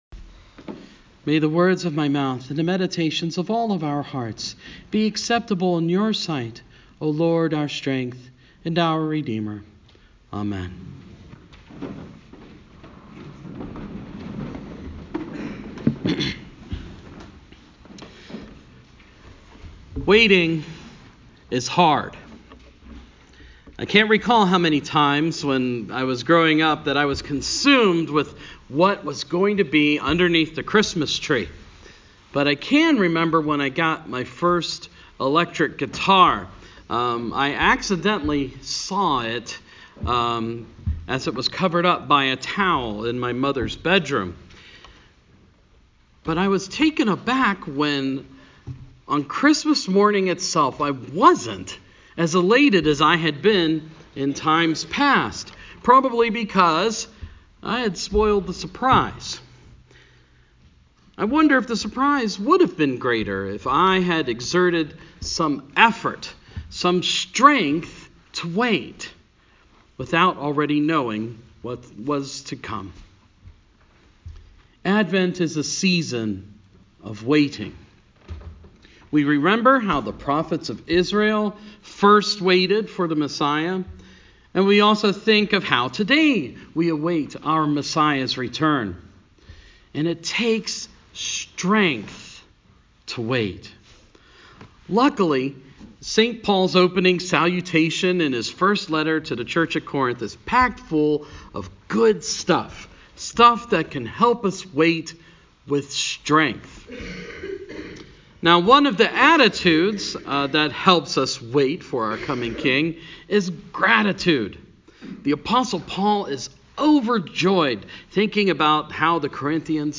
Sermon – First Sunday of Advent